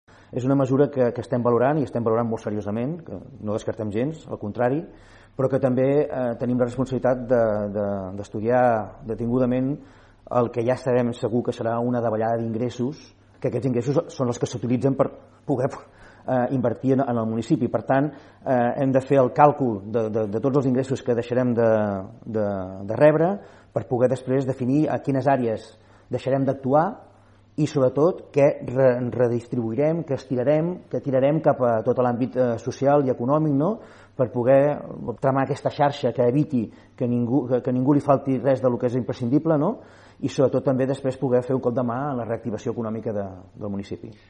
Dues setmanes després del decret d’estat d’alarma i del confinament obligat per evitar l’expansió del coronavirus, l’alcalde de Palafolls, Francesc Alemany, va concedir una entrevista a RP en la que va repassar l’actualitat d’aquests últims 15 dies i de l’estat actual de Palafolls en la lluita contra la COVID19.